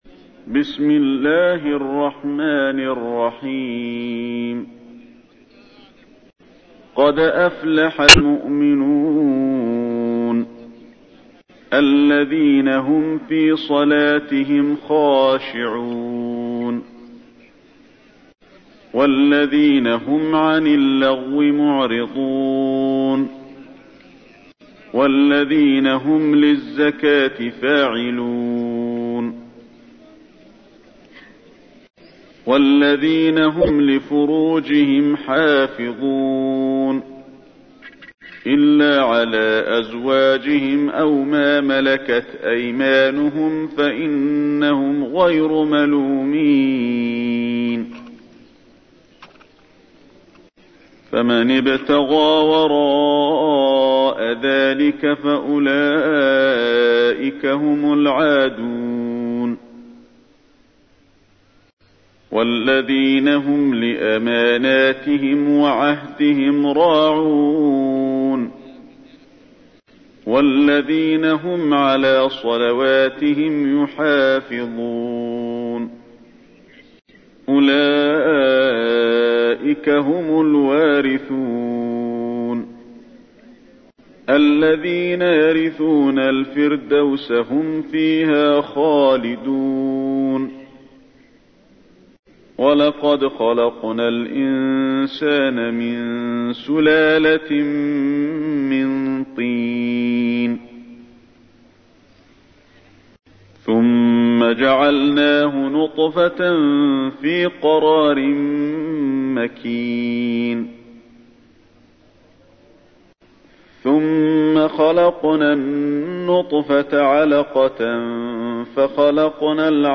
تحميل : 23. سورة المؤمنون / القارئ علي الحذيفي / القرآن الكريم / موقع يا حسين